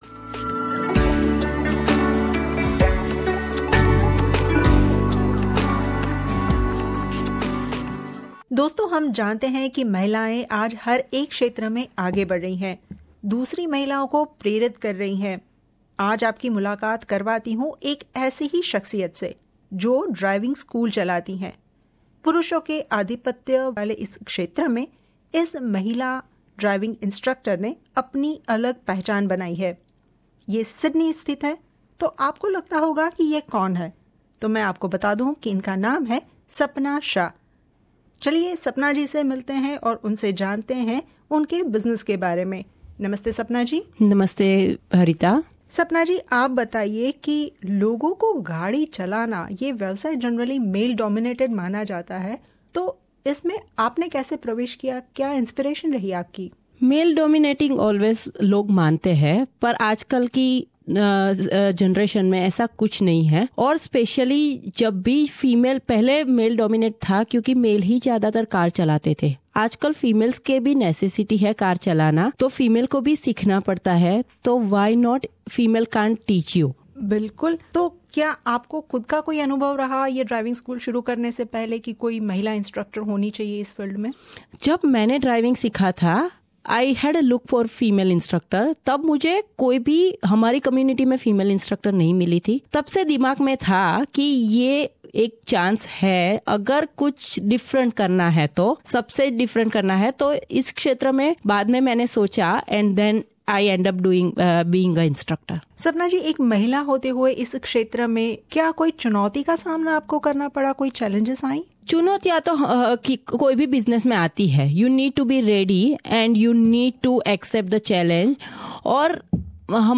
विशेष मुलाकात